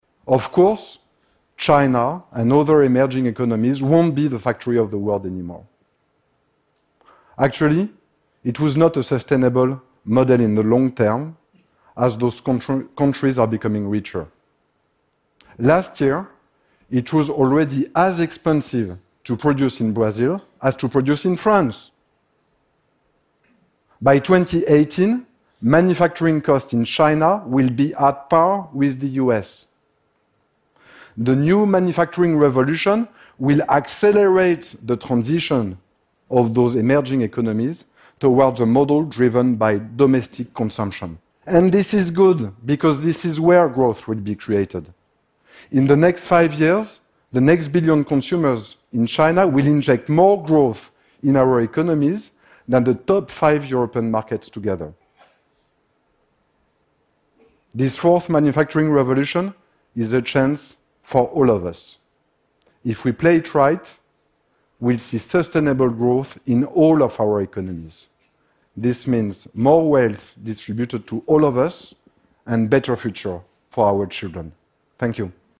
TED演讲:下一次工业革命就在眼前(8) 听力文件下载—在线英语听力室